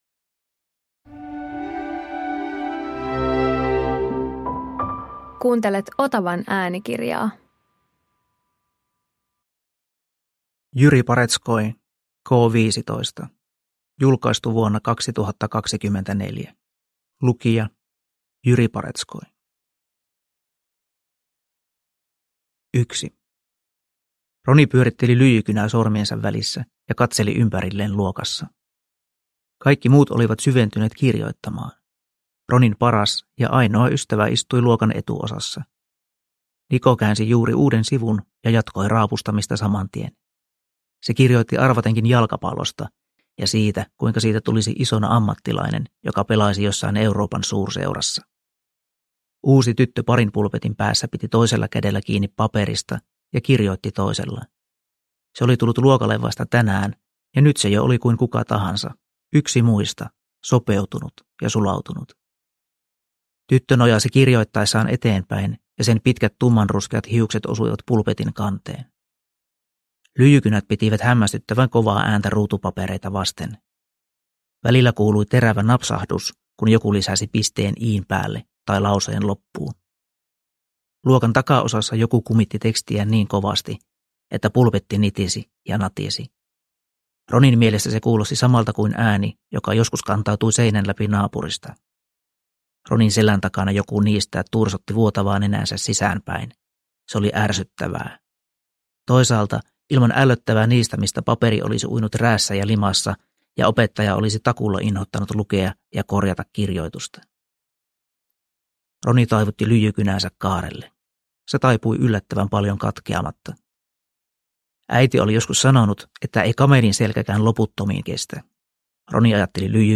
K15 – Ljudbok